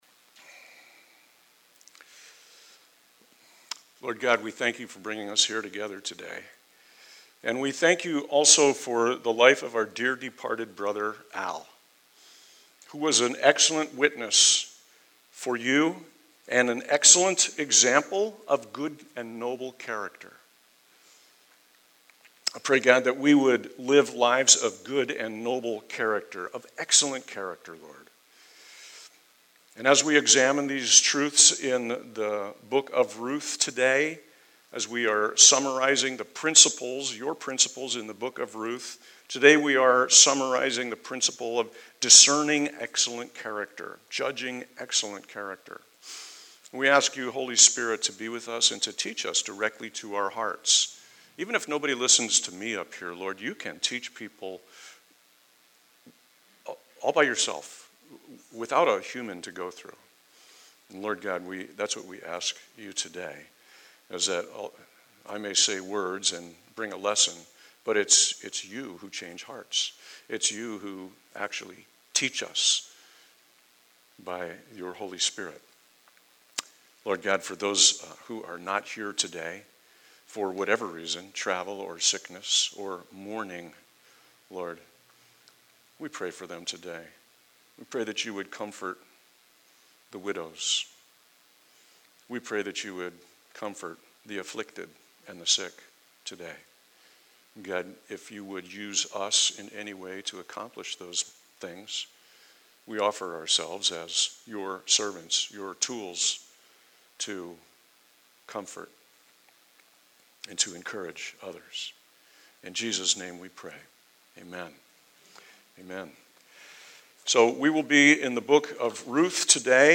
by Calvary Chapel Leesburg | Jan 9, 2022 | Sermons | 0 comments